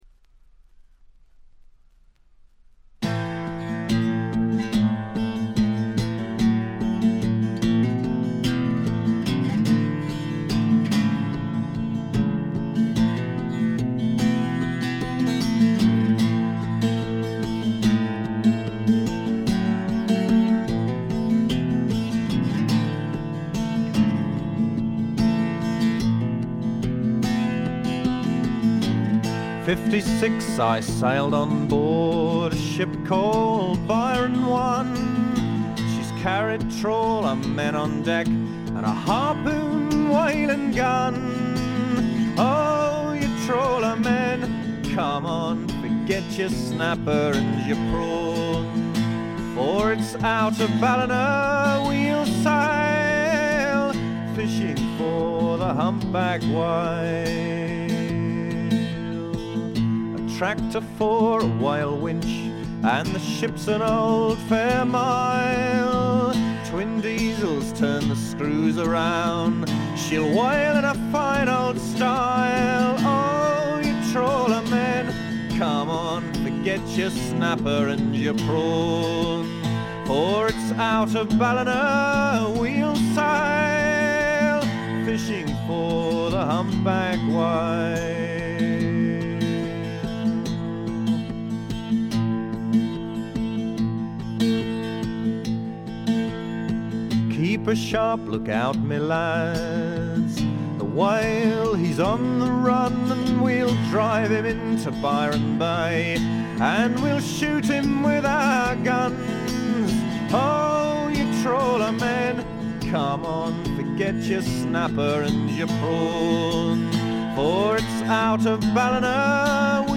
チリプチ少々。目立つノイズはありません。
芳醇で滋味あふれるヴォーカルにギターやフィドルも完璧です。
試聴曲は現品からの取り込み音源です。
Recorded At - Livingston Studios